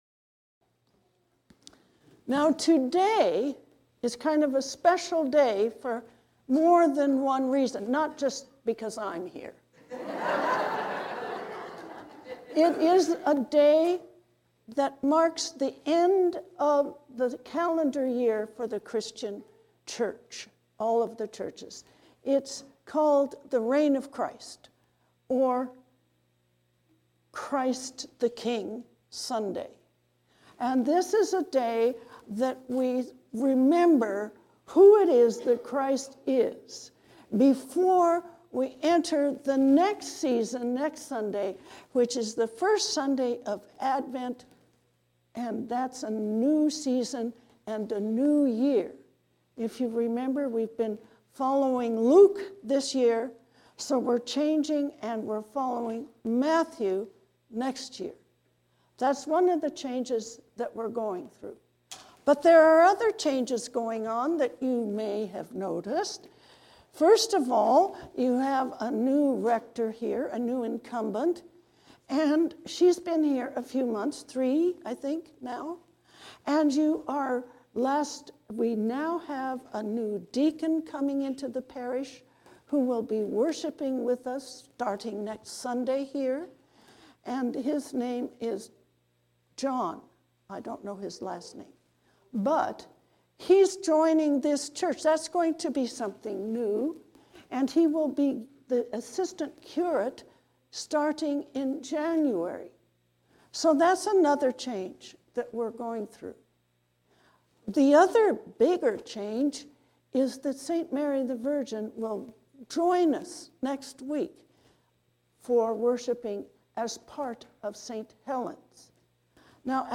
Sermon for the Reign of Christ